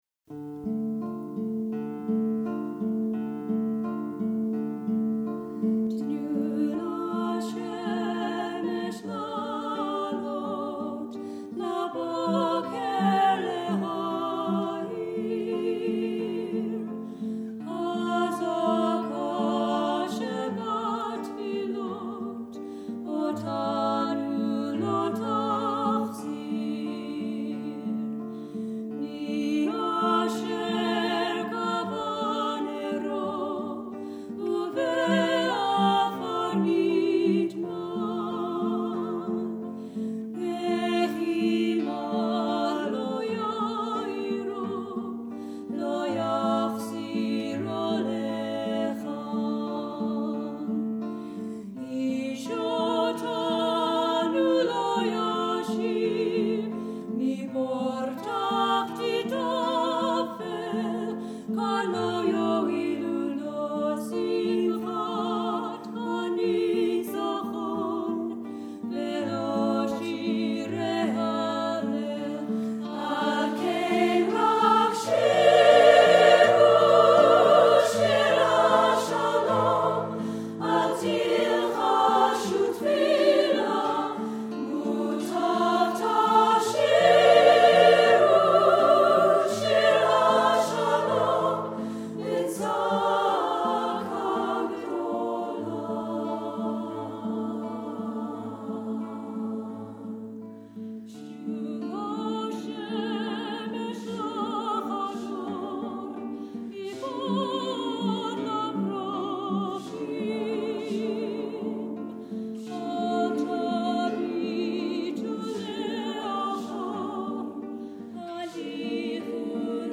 SSAA, piano or guitar